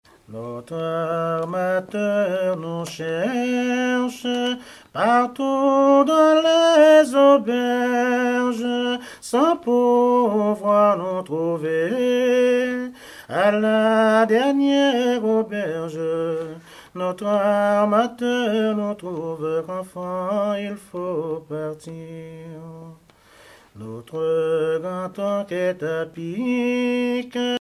chansons anciennes recueillies en Guadeloupe
Pièce musicale inédite